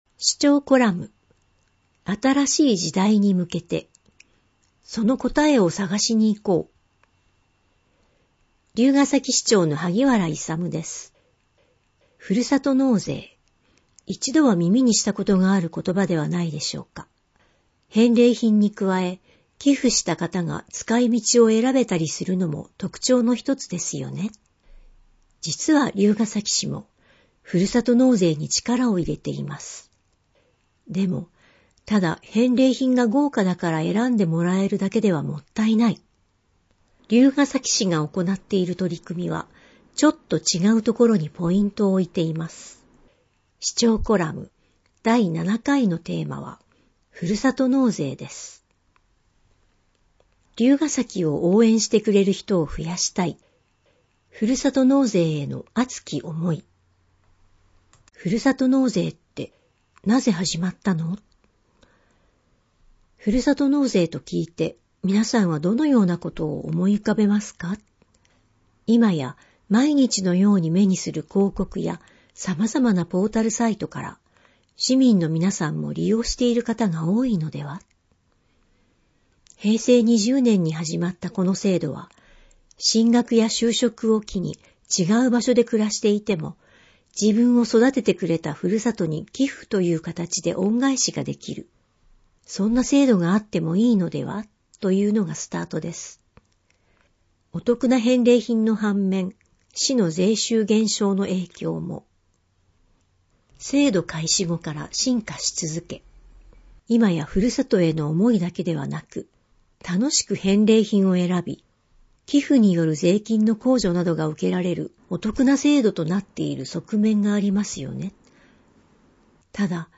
音訳データダウンロード
※MP3データは「龍ケ崎朗読の会」のご協力により作成しています。